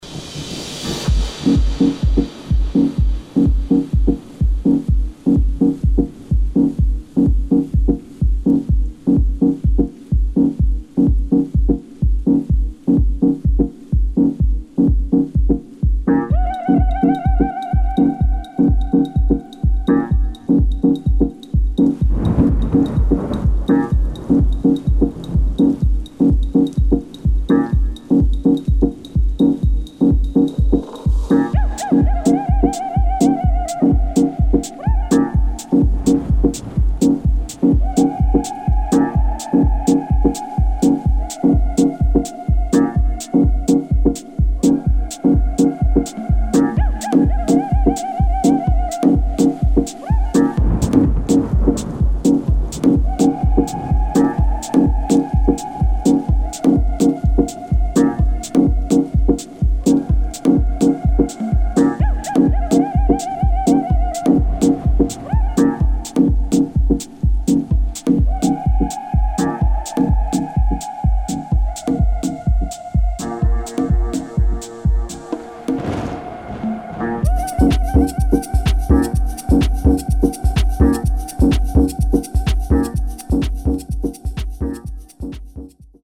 [ DEEP HOUSE | TECH HOUSE ]